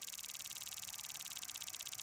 PrizeWheel.wav